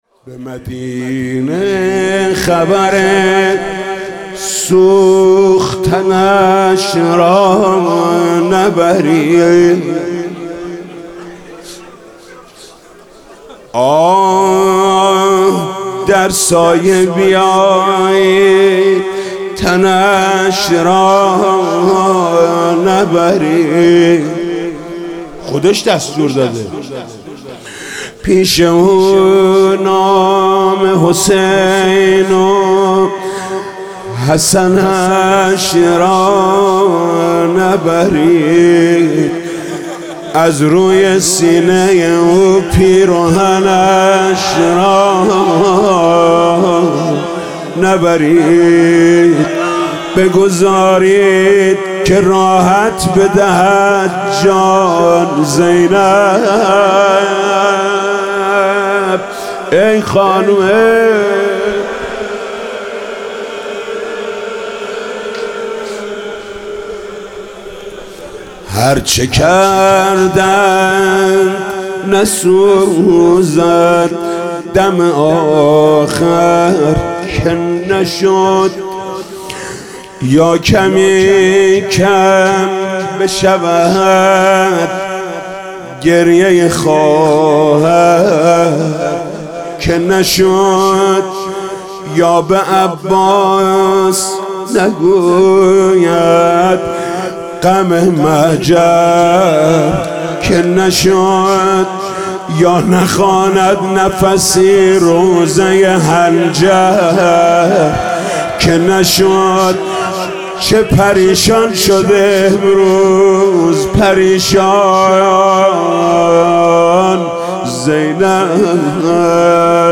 روضه: به مدینه خبر سوختنش را نبرید